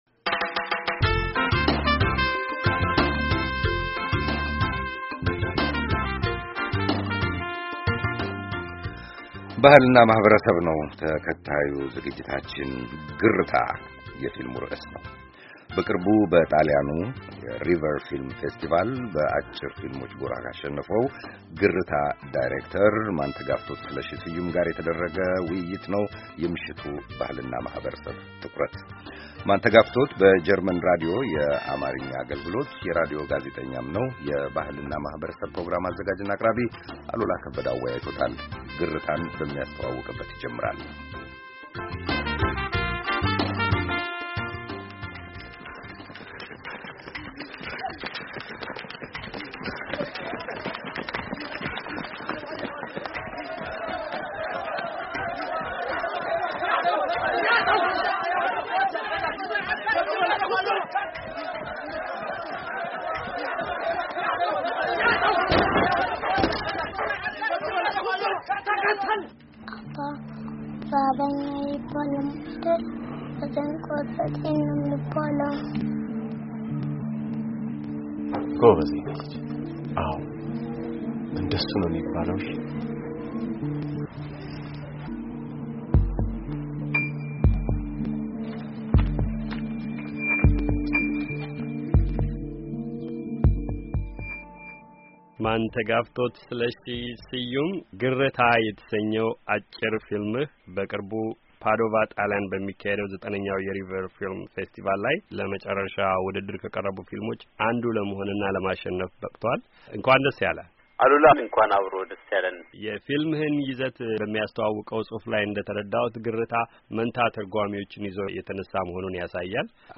ወግ